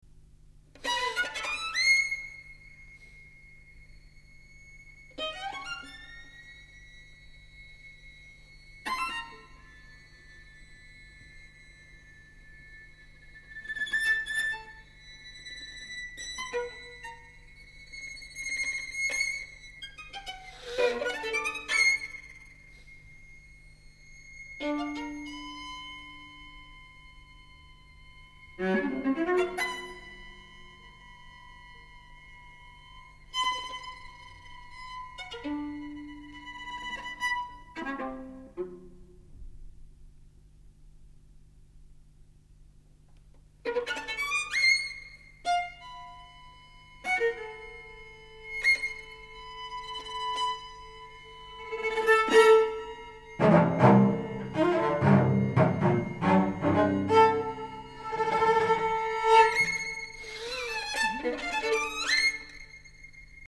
String Quartet III